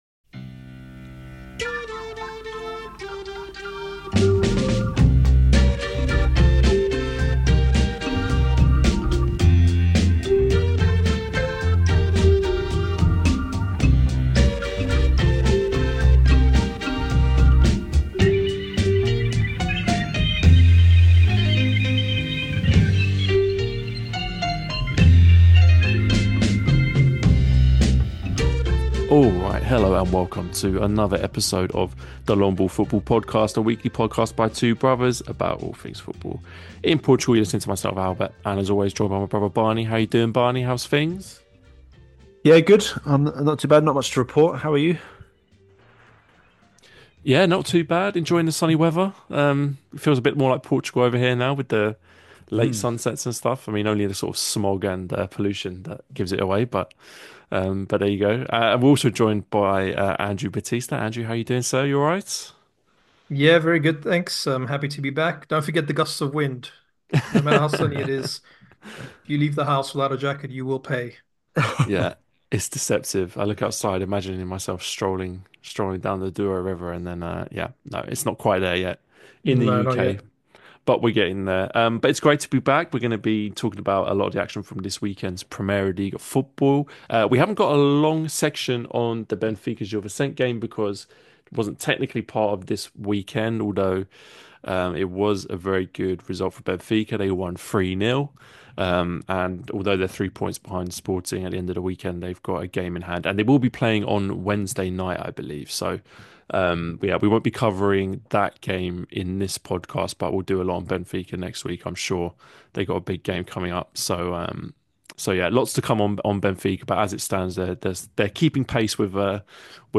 A weekly podcast by two brothers about all things football in Portugal 🇵🇹⚽🇬🇧 Join us each week for Primeira Liga chat, and discussion about Portuguese clubs' exploits in Europe!